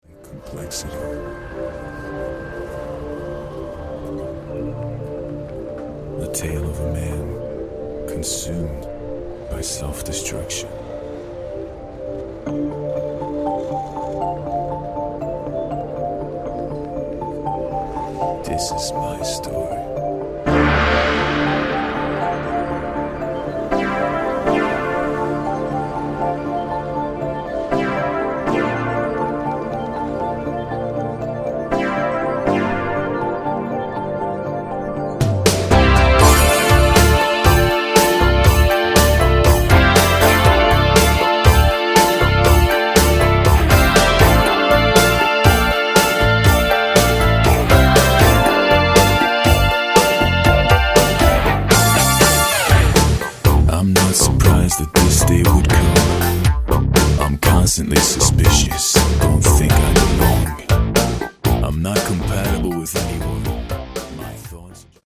Category: AOR
Guitars, Keyboards, Bass, Programming
Lead & Backing Vocals, Keyboards